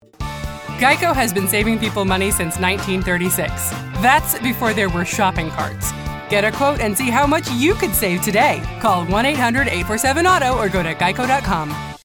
Geico Radio Ad Demo
My voice is warm and comforting, relatable, humorous, and authoritative. As a natural alto I am very comfortable in low, smokey and intimate ranges.